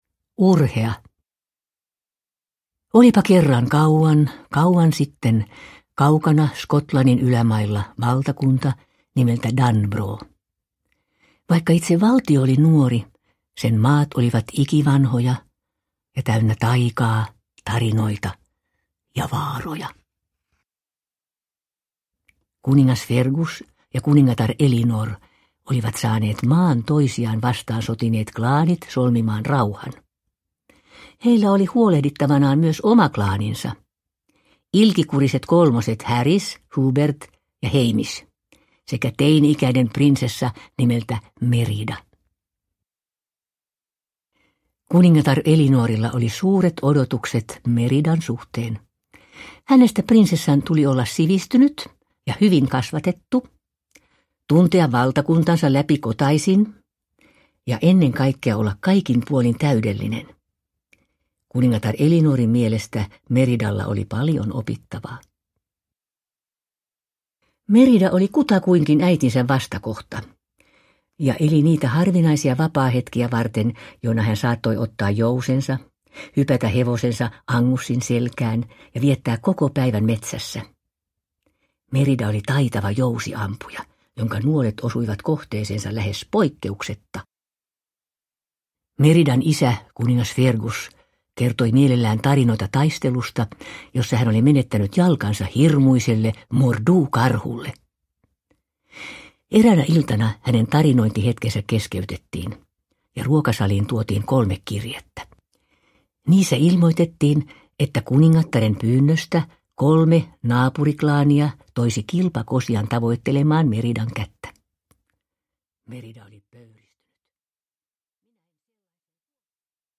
Urhea – Ljudbok – Laddas ner
Uppläsare: Seela Sella